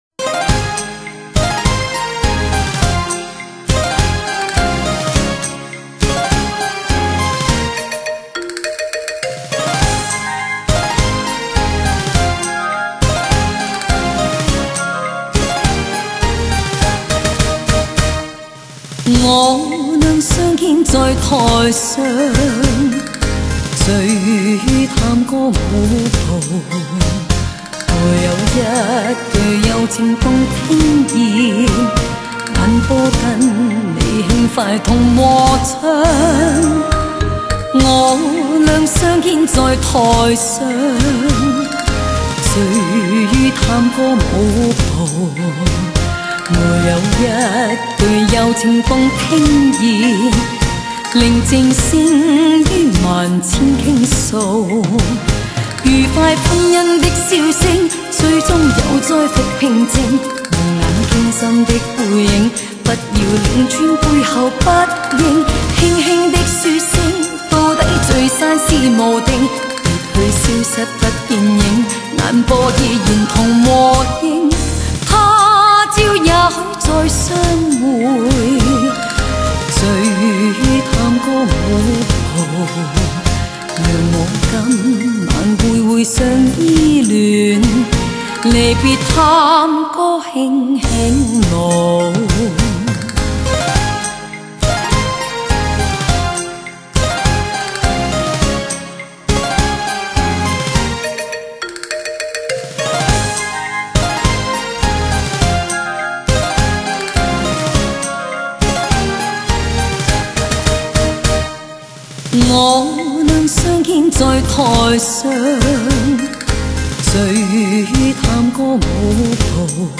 交际舞曲各放异彩
Tango